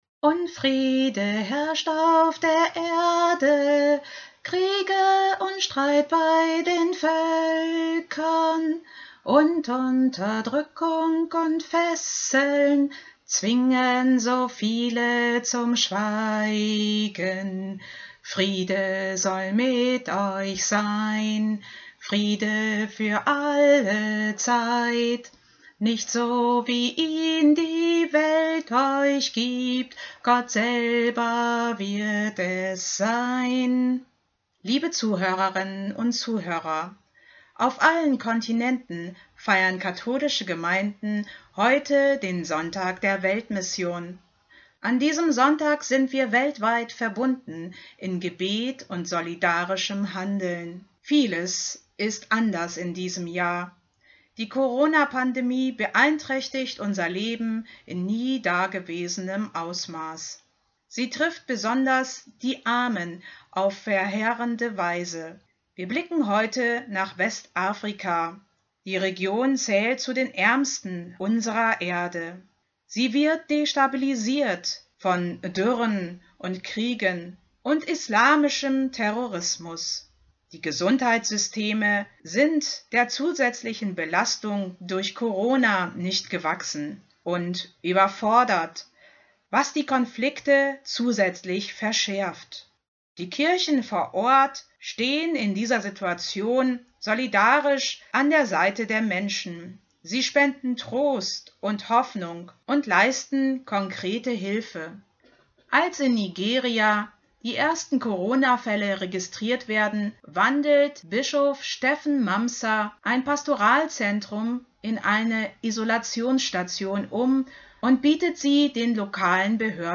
„Selig, die Frieden stiften“ (Mt 5,9) – Sonntagsimpuls 25.10.2020